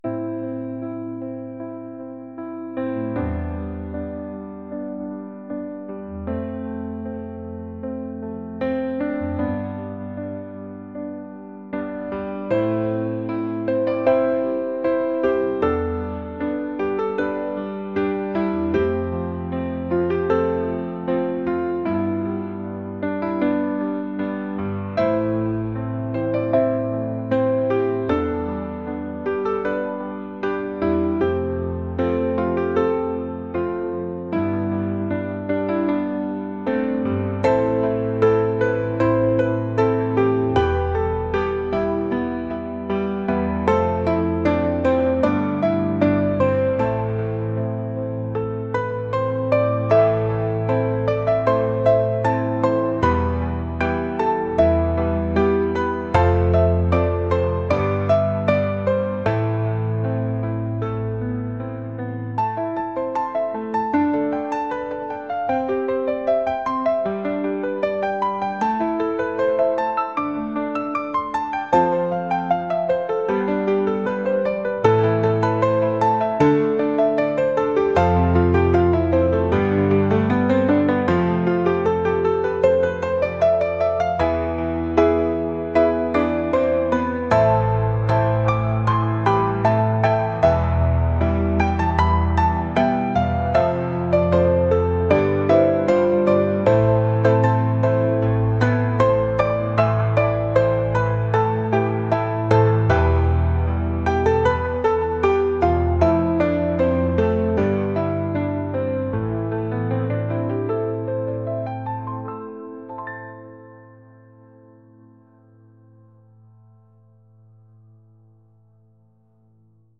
pop | ambient | cinematic